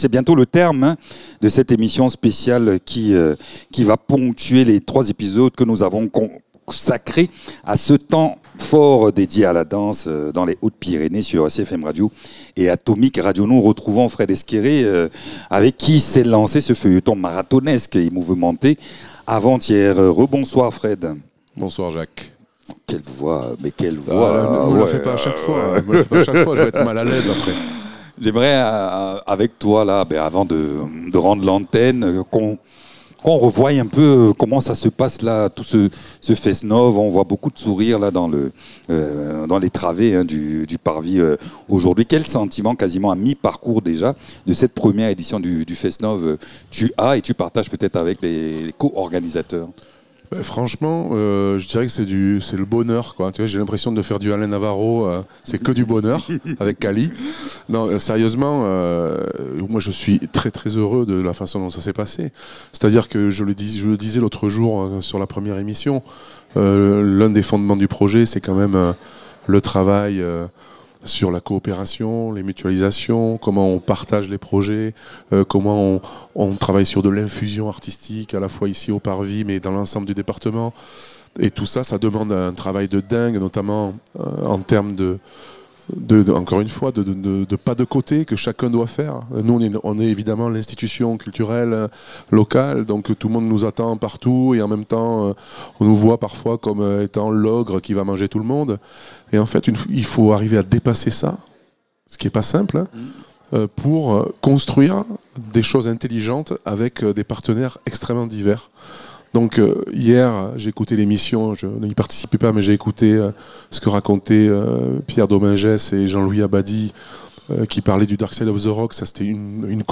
À mi-parcours du festival, dressons un premier bilan de ce temps fort dédié à la danse. L’occasion aussi de souligner l’importance des collaborations entre structures culturelles pour enrichir la programmation et créer du lien avec les publics. Cette discussion ouvre également sur les perspectives à venir, entre projets futurs et ambitions pour la scène artistique locale et régionale.